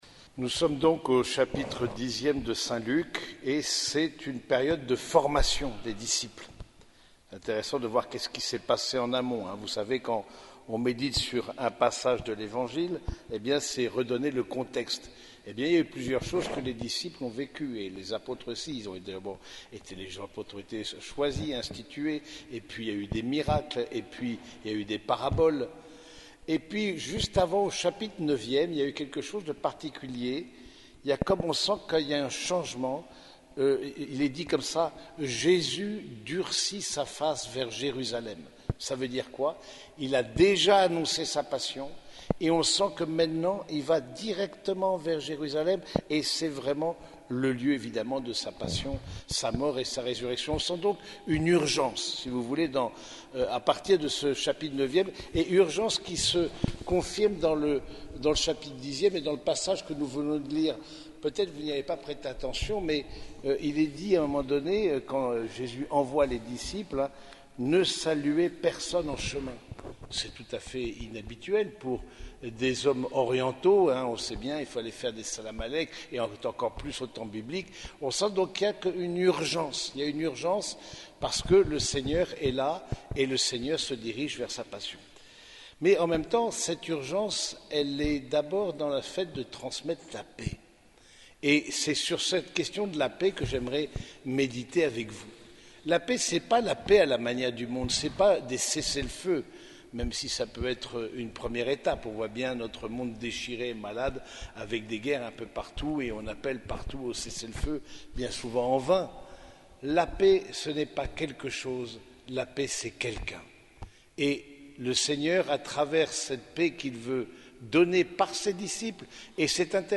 Homélie du deuxième dimanche de Pâques - Dimanche de la miséricorde